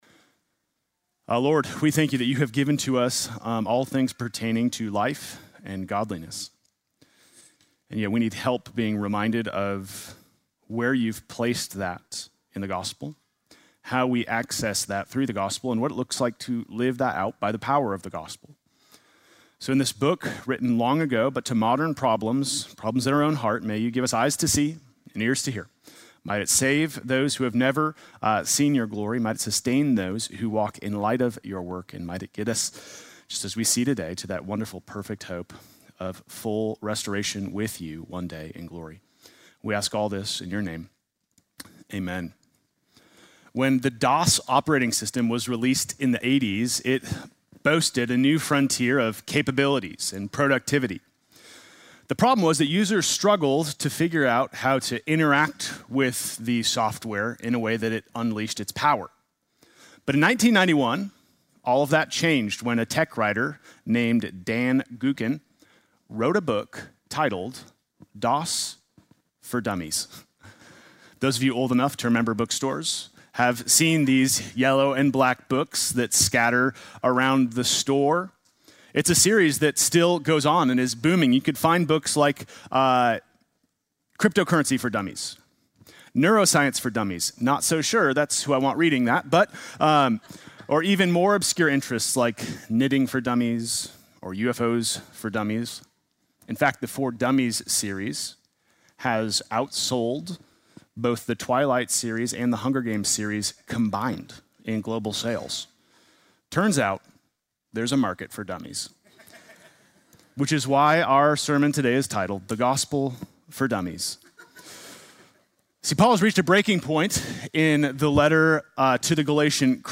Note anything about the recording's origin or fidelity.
Sunday morning message November 23